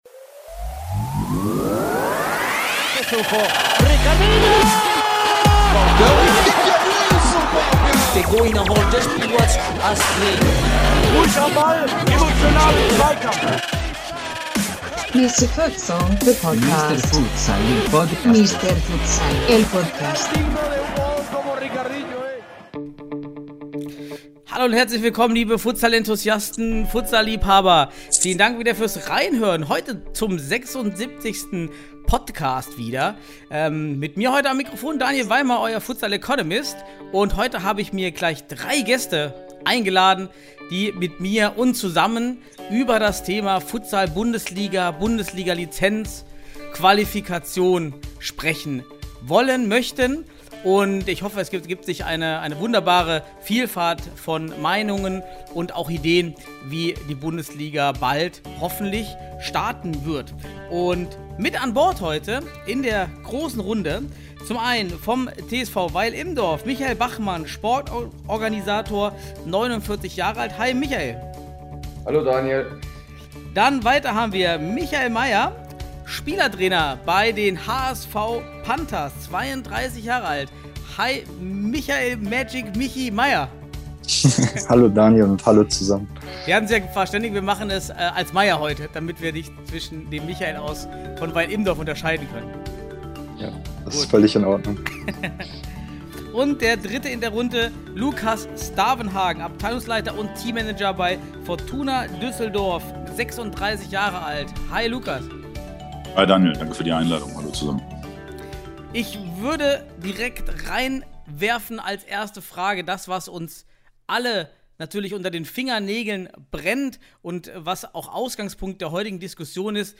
#76: Bundesliga-Talk: Hamburger SV, Fortuna Düsseldorf & TSV Weilimdorf ~ Futsal Podcast